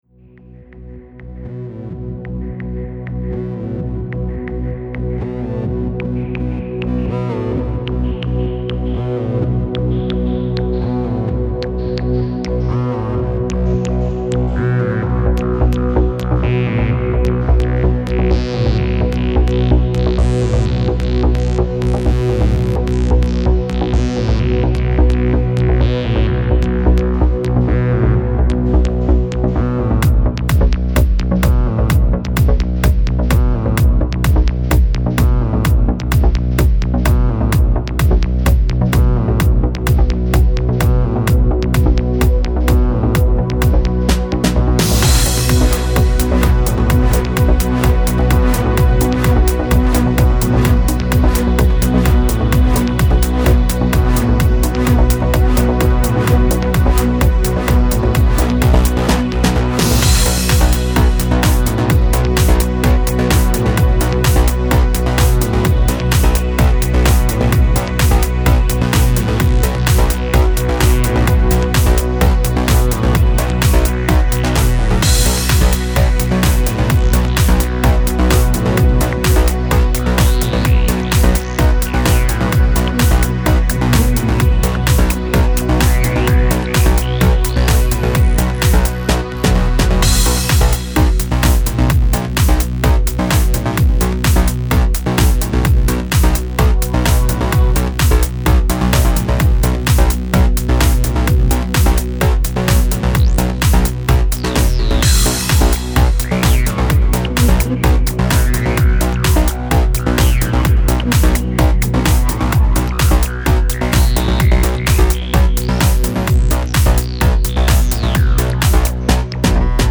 вот сегодня баловался следующим образом: пустил все 4 канала снежного на одну шину и поставил запись аудио дороги с этой шины - запись шла в реалтайм причем я в плагине виря крутил еще и фильтры; потом что-бы не обидно было перегнал так в аудио дорогу же через другую шину свои миди друмсы;
щелчков и затыков не наблюдалось;